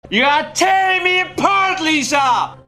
death4.wav